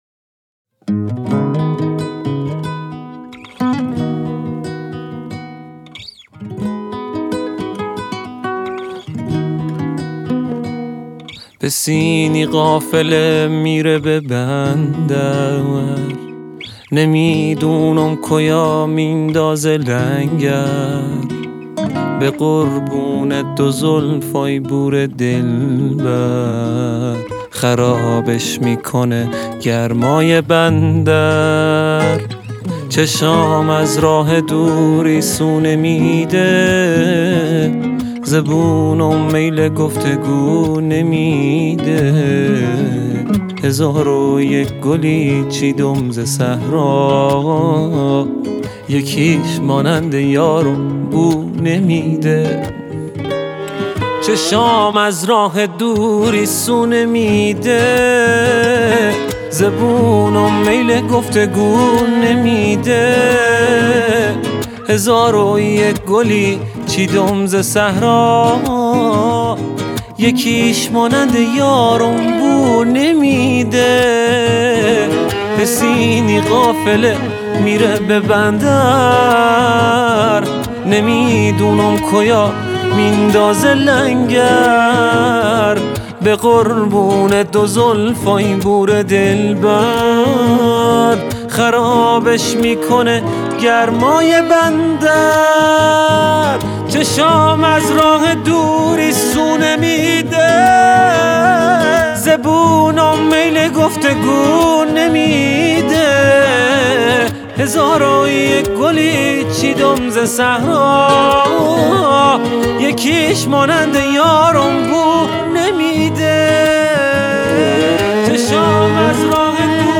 ترانه جنوبی